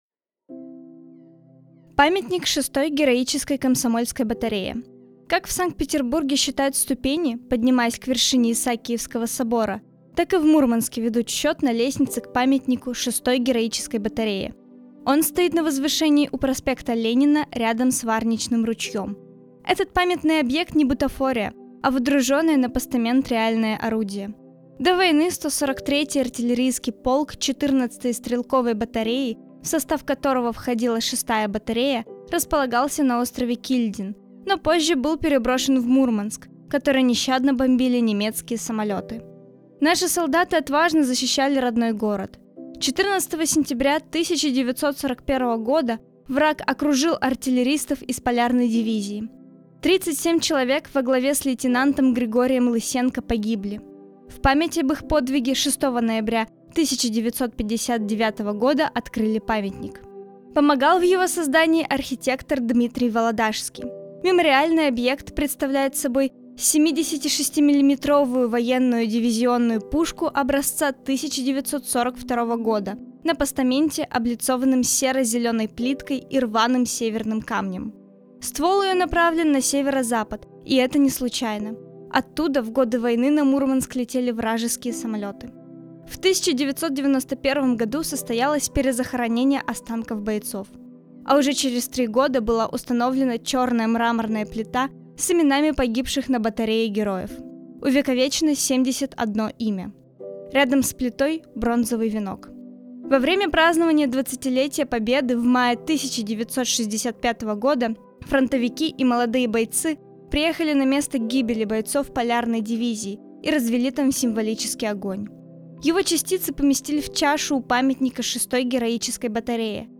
Представляем очередную аудиоэкскурсию, подготовленную волонтерами библиотеки, в рамках туристического проекта «51 история города М»